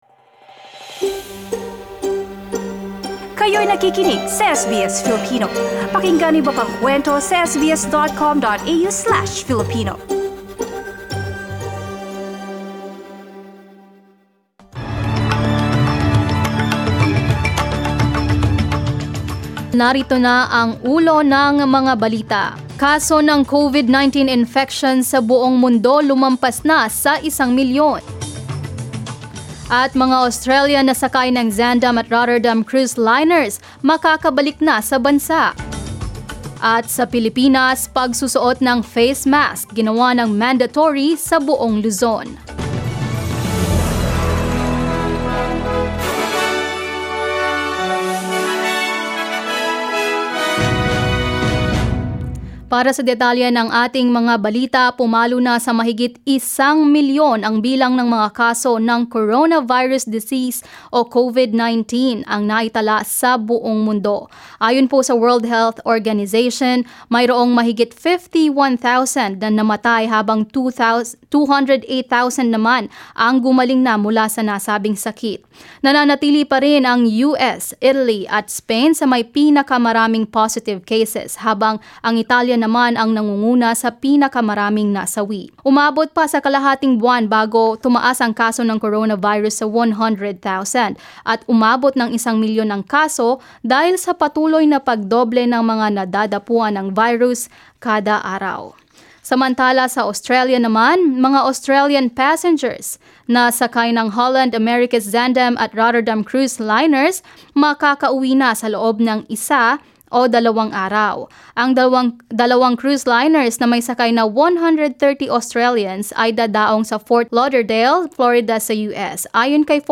SBS News in Filipino, Friday 3 April